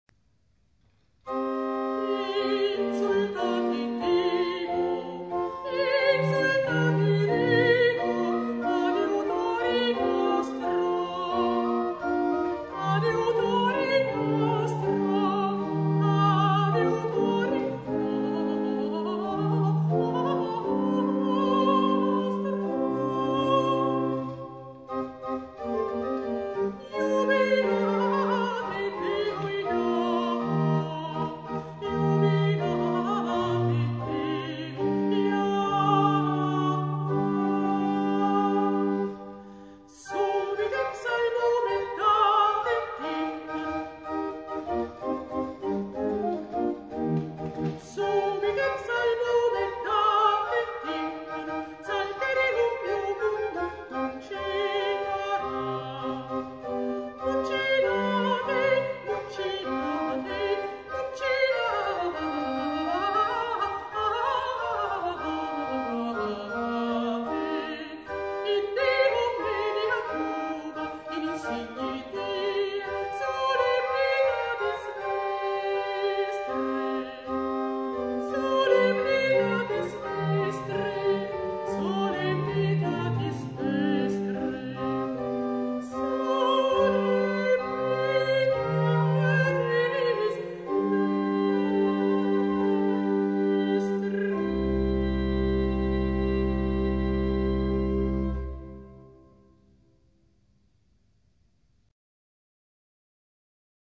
GenereMusica Classica / Antica